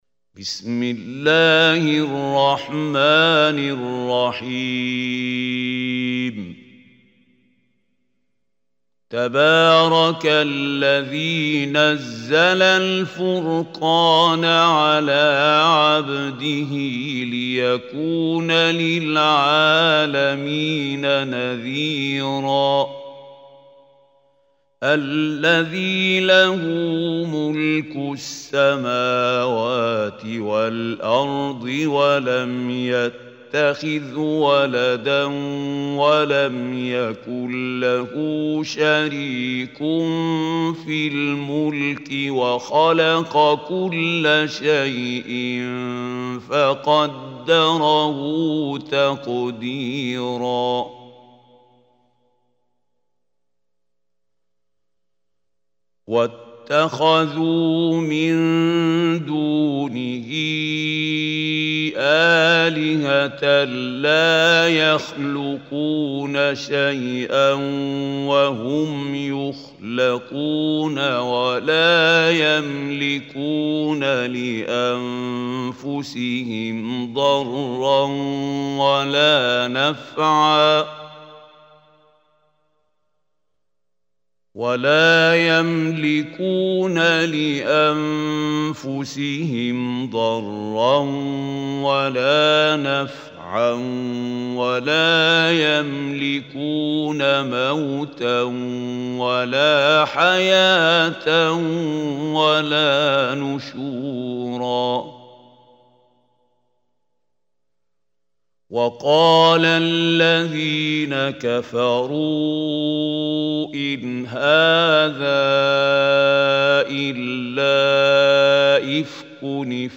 Surah Furqan Recitation by Mahmoud Khalil Hussary
Surah Furqan is 25 Surah of Holy Quran. Listen or play online mp3 tilawat / recitation in arabic in the beautiful voice of Mahmoud Khalil AL Hussary.